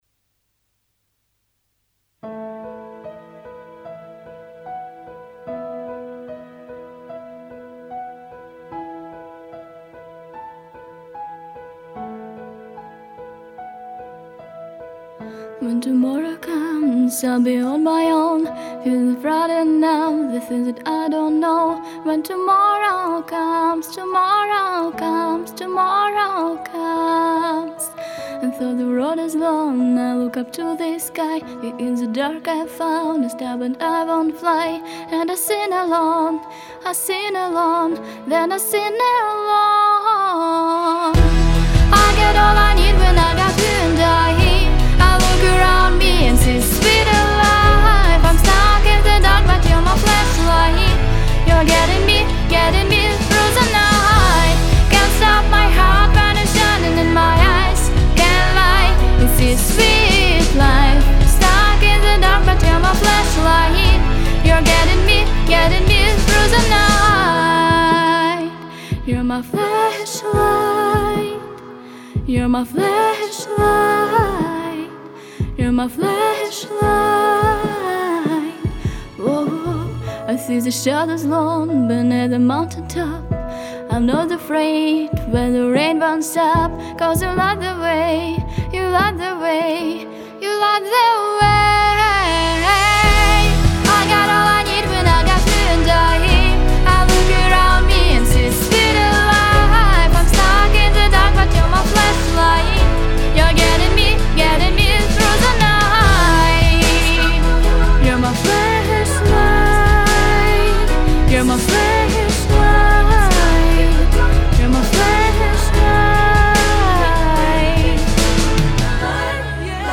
Сопрано Меццо-сопрано